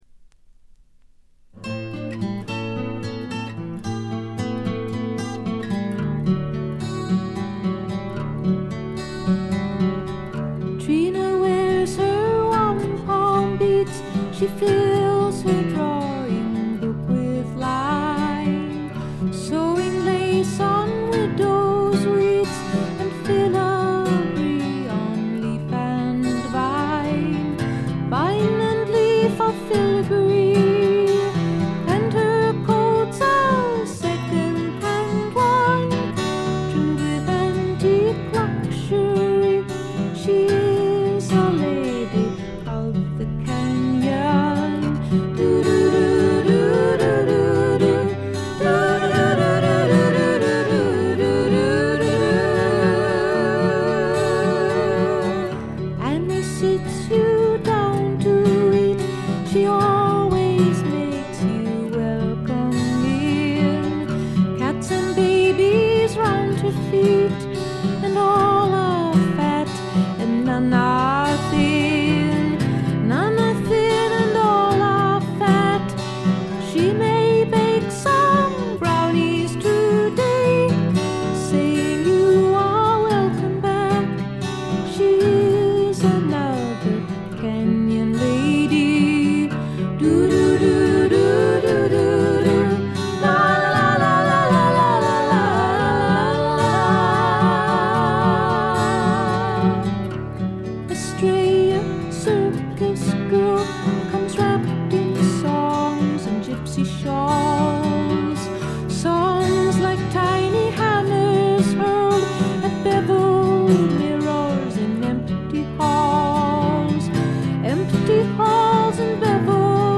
軽微なチリプチ少々。散発的なプツ音が2-3箇所。
美しいことこの上ない女性シンガー・ソングライター名作。
試聴曲は現品からの取り込み音源です。
Recorded At - A&M Studios